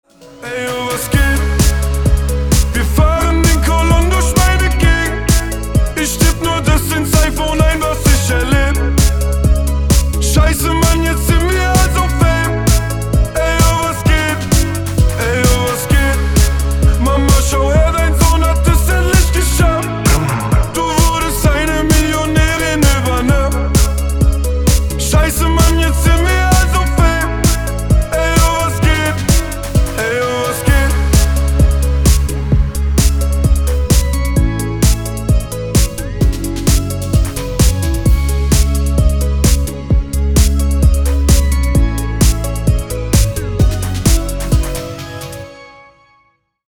мужской вокал
электронная музыка
pop-rap
зарубежный рэп
Hip-Hop